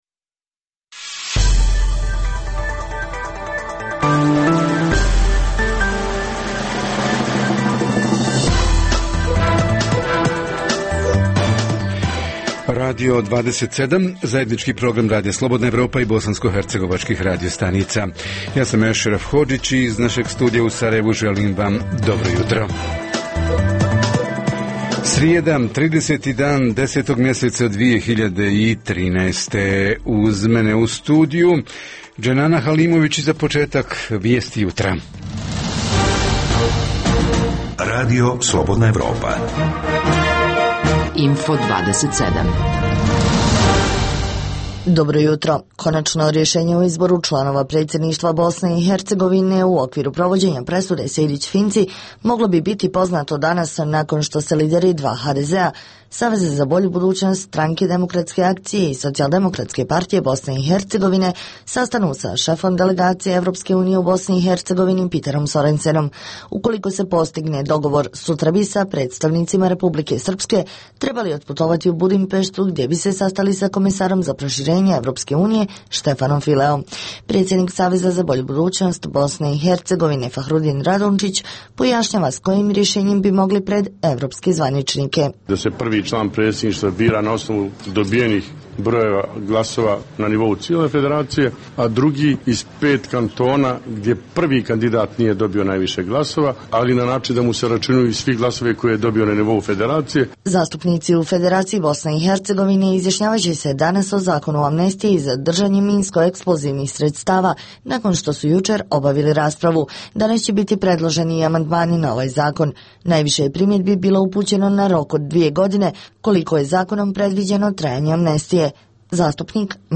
Radio 27 jutros donosi: - Info-plus i susret „uživo“ sa Sarajevom: hoće li kantonalna vlada konačno, uz odluku o novoj cijeni centralnog grijanja, naći i sredstva za subvencije onima kojima će to bitnije ugorziti životni standard?
- Središnja tema ovog jutra: inspektori u akciji – u kojim su oblastima najangažovaniji i najdjelotvorniji? O tome će naši reporteri iz Višegrada, Tuzle i Zvornika.